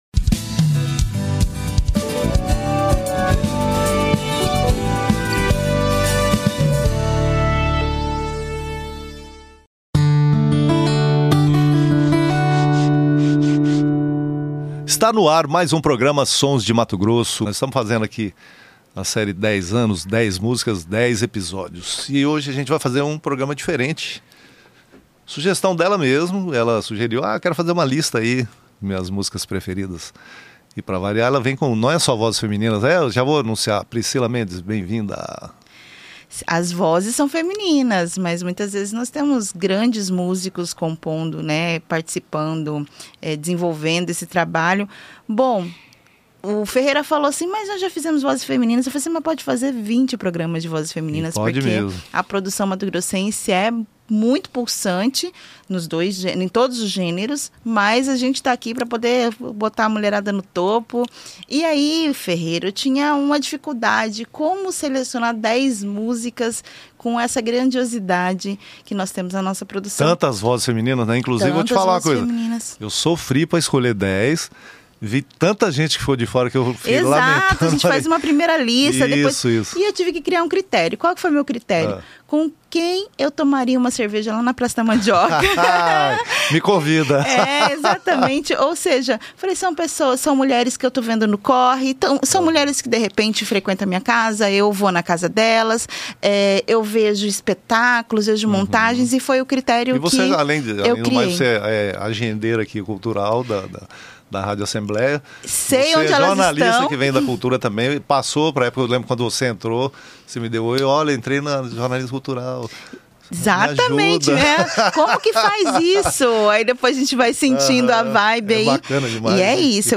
uma seleção de 10 músicas de cantoras e compositoras mato-grossenses.